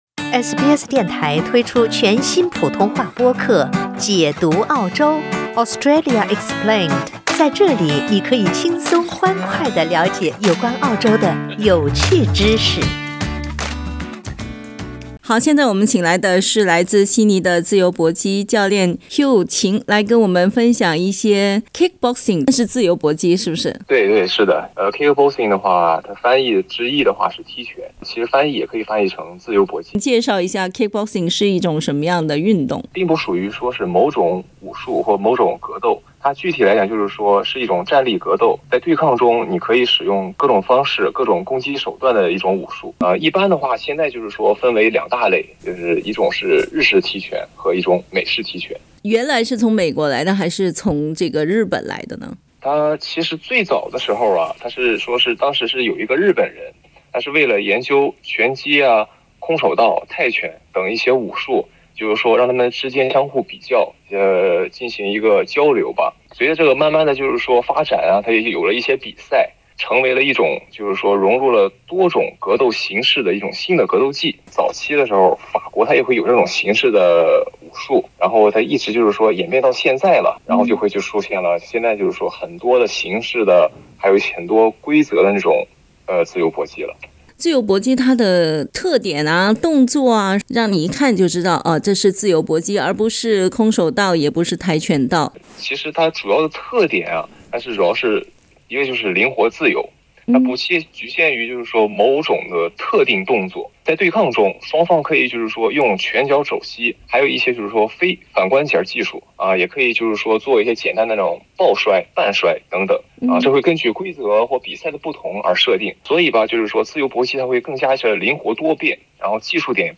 自由搏击在英文里称为“kick boxing”，它结合了各国武术的技法，竞技时比较自由。（点击图片收听采访）